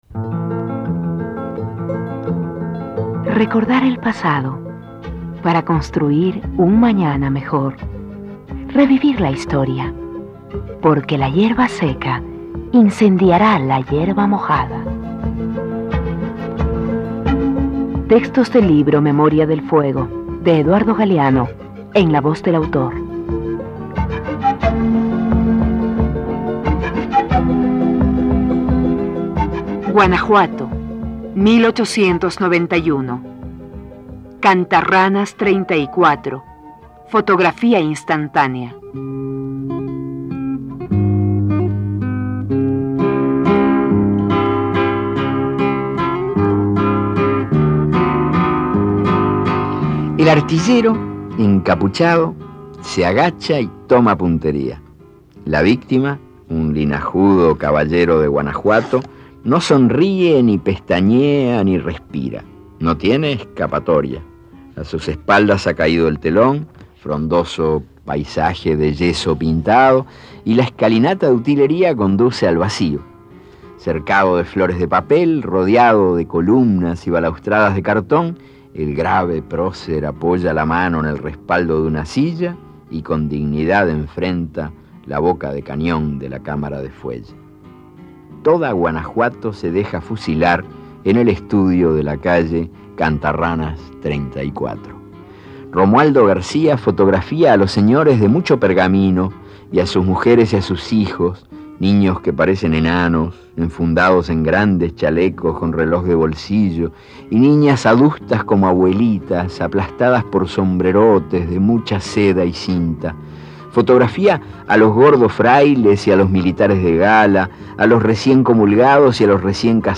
Este archivo de sonido ofrece la lectura del texto en la voz de su autor.